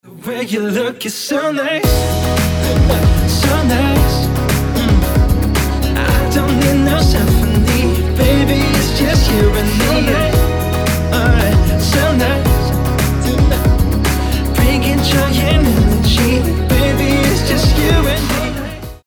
A fresh funk-infused pop song.